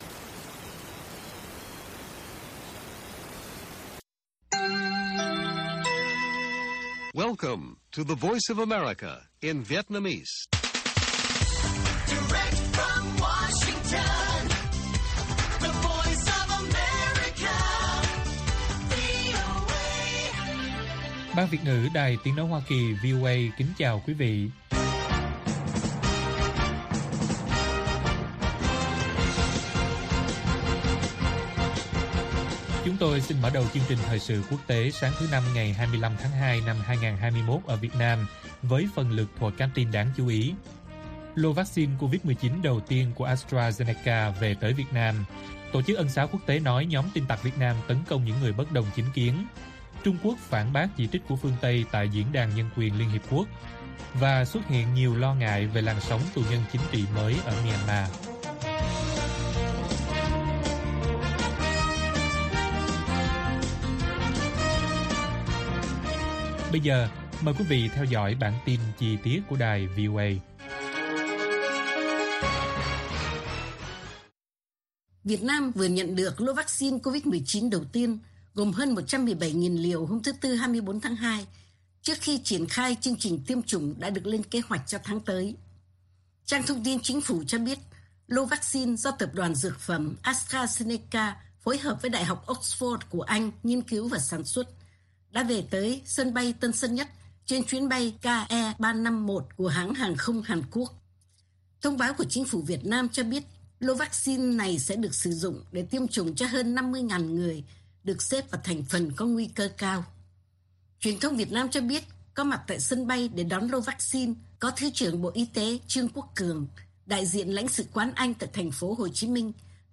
Bản tin VOA ngày 25/2/2021